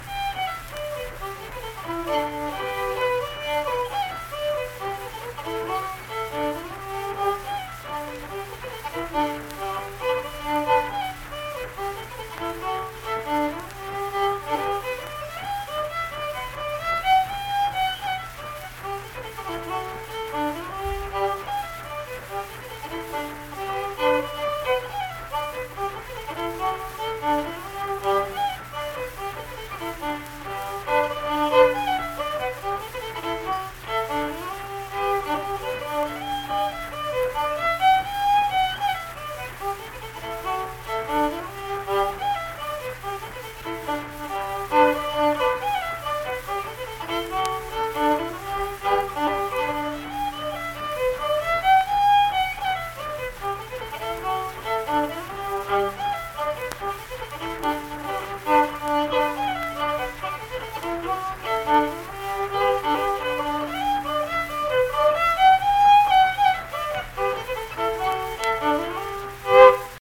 Unaccompanied fiddle music performance
Verse-refrain 4(2).
Instrumental Music
Fiddle